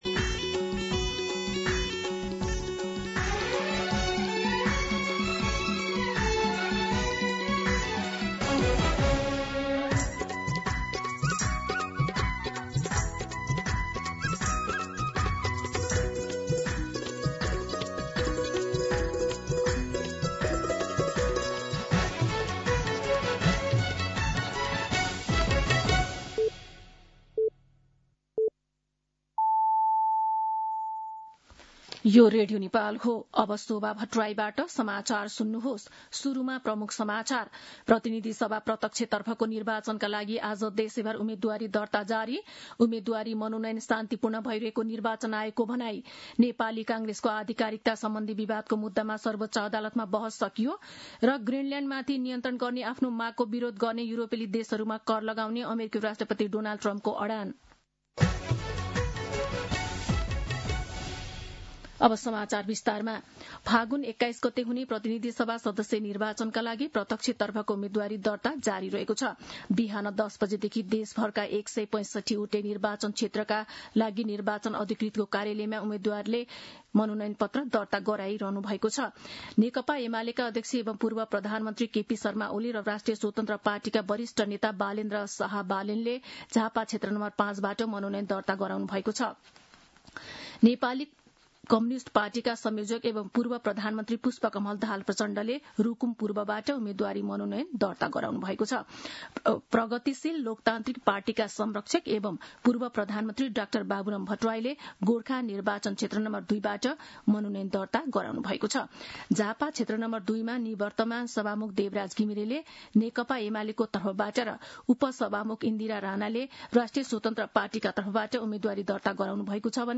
दिउँसो ३ बजेको नेपाली समाचार : ६ माघ , २०८२